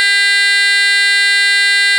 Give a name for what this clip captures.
oboe: sound-oboe.wav